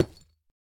Minecraft Version Minecraft Version snapshot Latest Release | Latest Snapshot snapshot / assets / minecraft / sounds / block / deepslate / place3.ogg Compare With Compare With Latest Release | Latest Snapshot